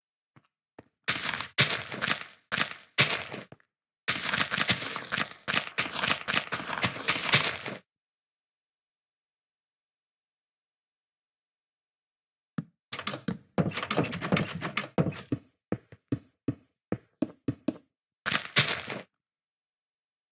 Just toggle HRTF in the sound options and hear the difference - there is a difference in the sound when you click on the UI in 1.20.1, but no difference in 1.20.2.{*}{*}
HRTF turned on (1.20.1).m4a